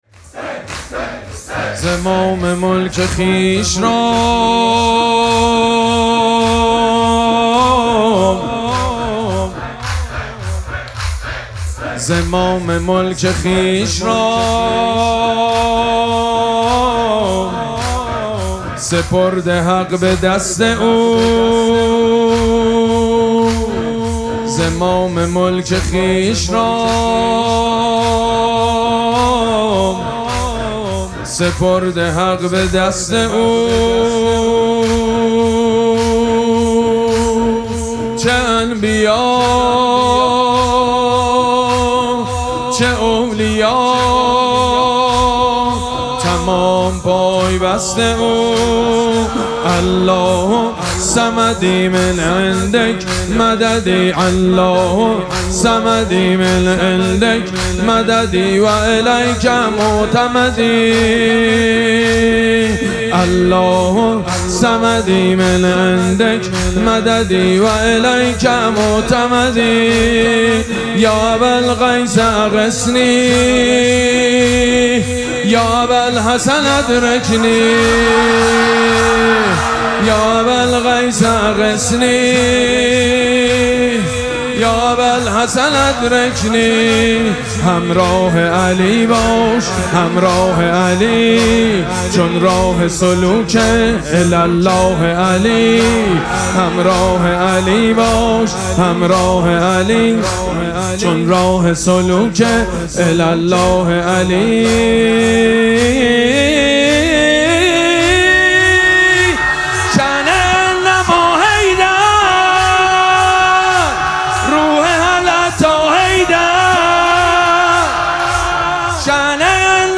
مراسم مناجات شب بیست و یکم ماه مبارک رمضان
مداح
حاج سید مجید بنی فاطمه